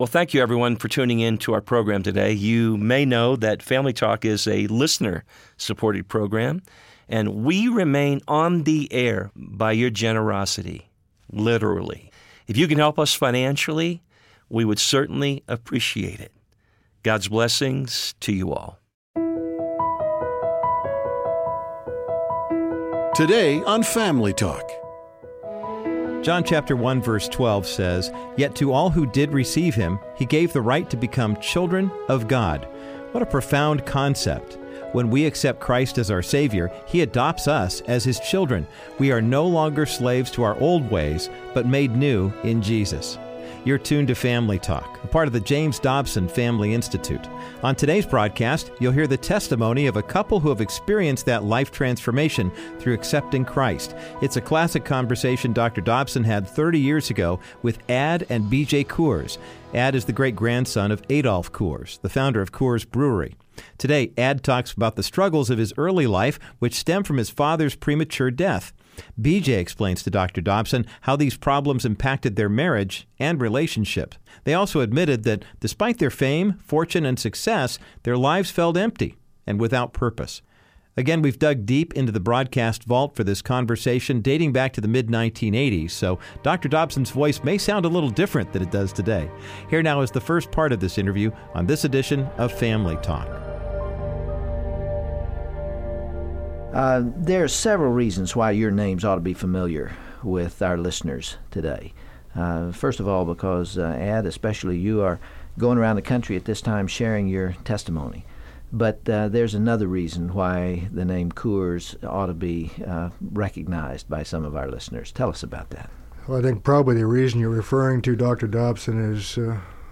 youll hear a classic interview